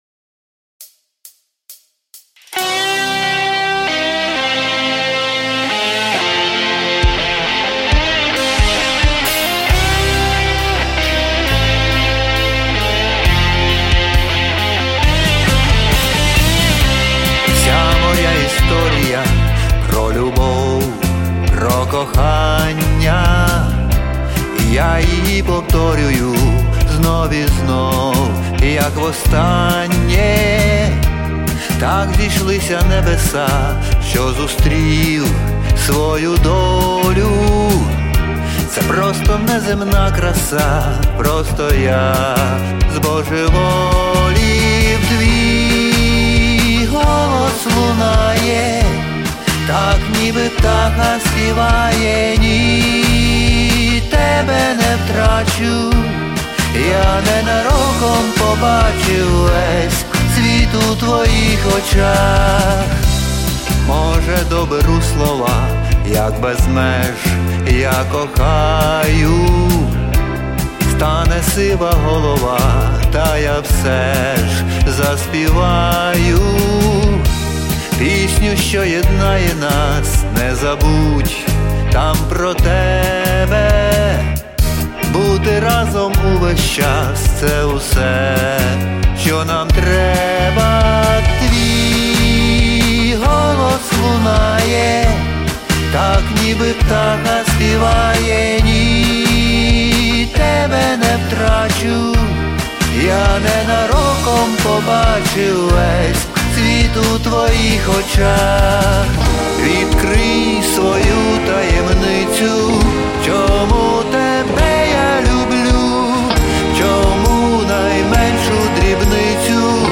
Стиль: Поп рок